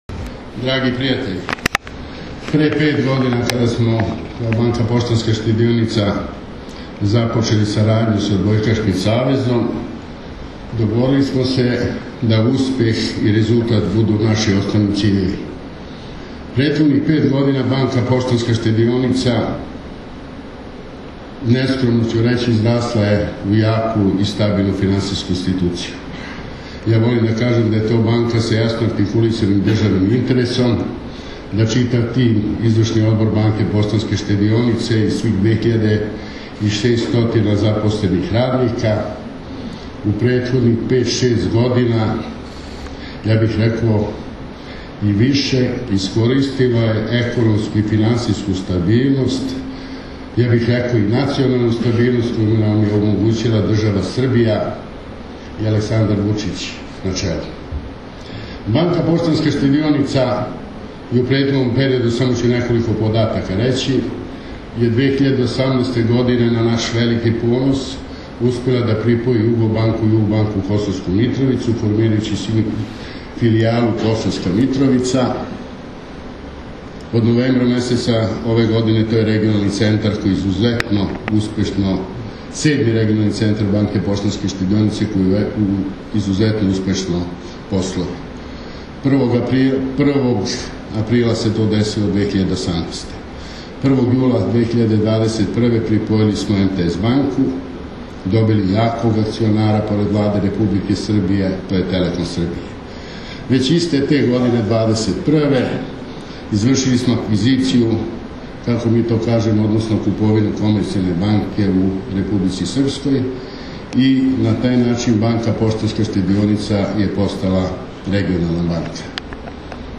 OSS – NOVOGODIŠNJI KOKTEL